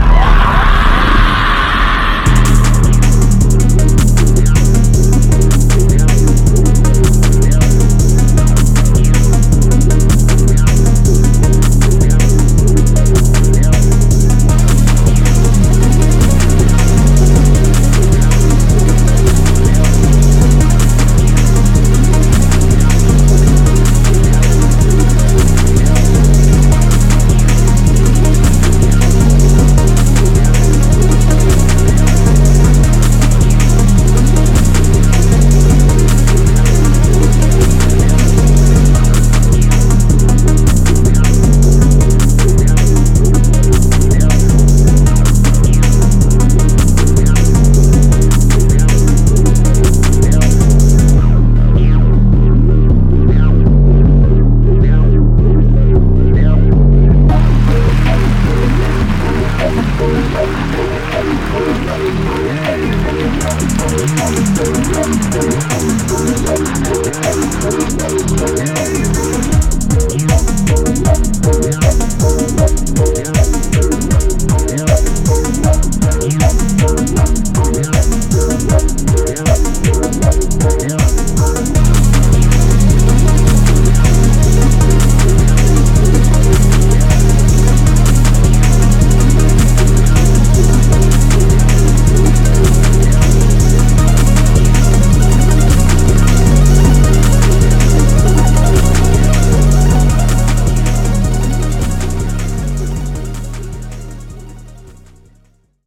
There’s no clean rhythm to hold onto.